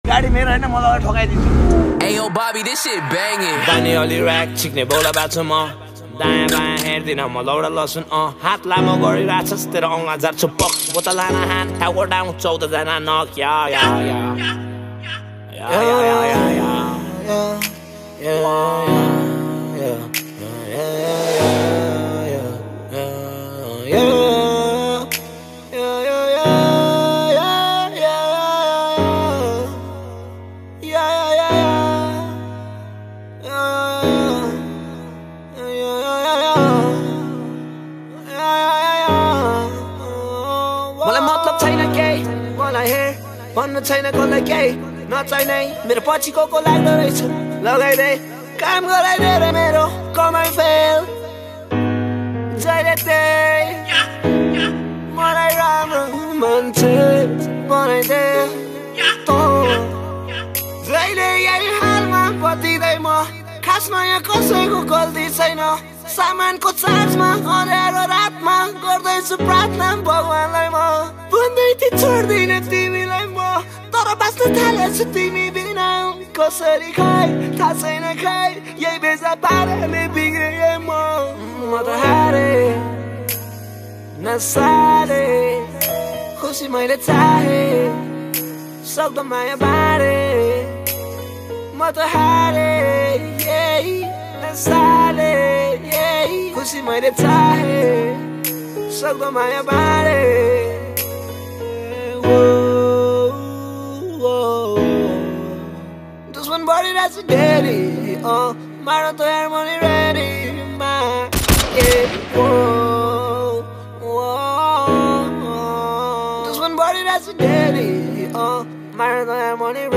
# Nepali Rap Mp3 Songs Download